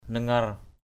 /nə-ŋʌr/ (d.) dây thừng, chằn cột buồm = hauban, grande corde. rope, a rope using to mast sails. talei nangar tl] nZR dây thừng. rope
nangar.mp3